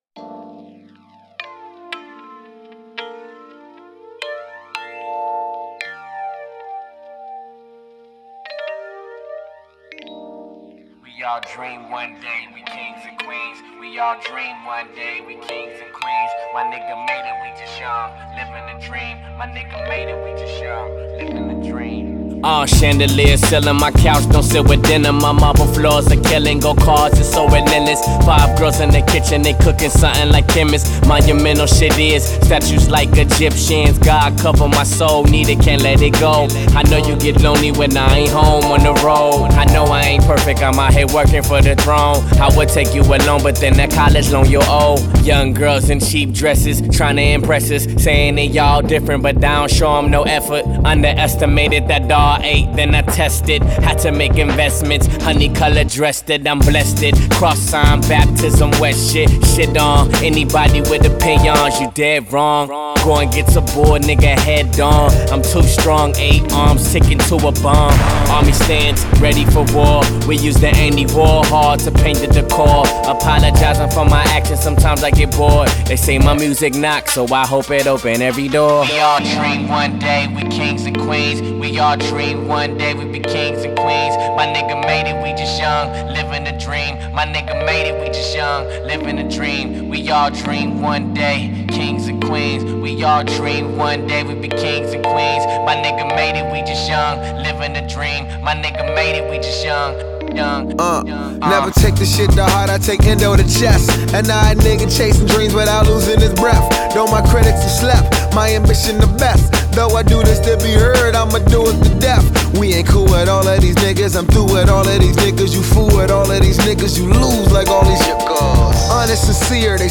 low-key, soothing production